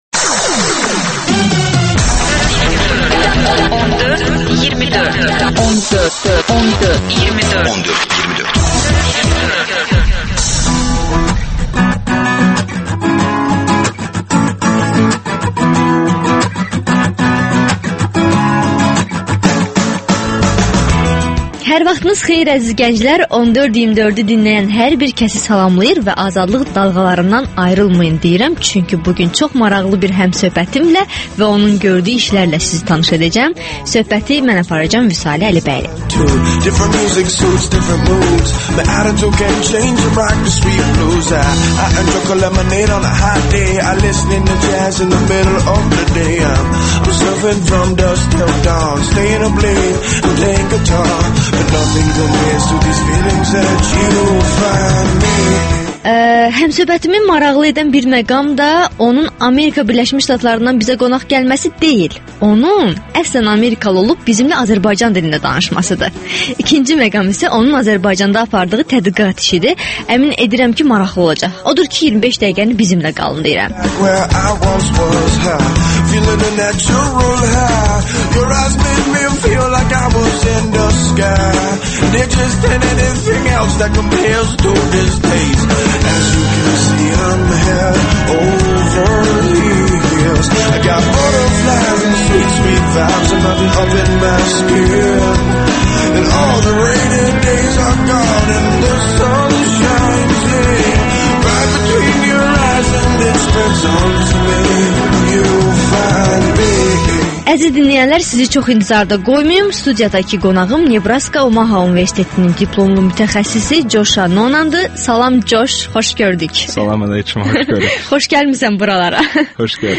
Gənclər üçün xüsusi verilişdə: Okeanın o tayından gələn qonağımı maraqlı edən nəinki onun apardığı tədqiqatdır, həm də onun əslən amerikalı olduğu halda Azərbaycan dilində bizə müsahibə verməsidir.